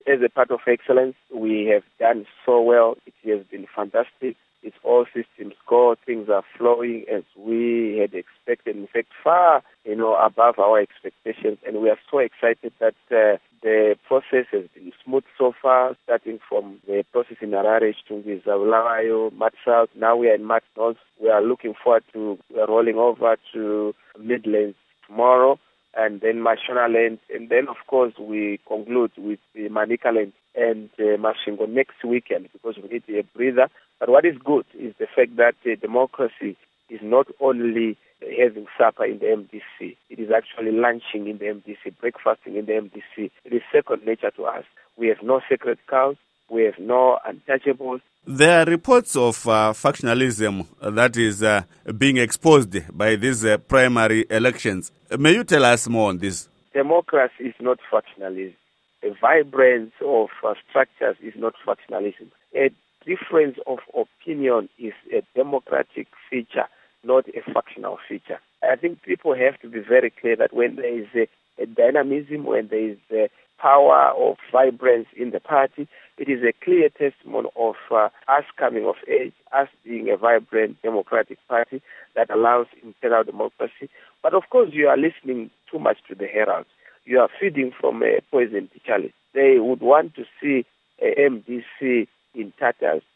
Embed share Interview With Nelson Chamisa by VOA Embed share The code has been copied to your clipboard.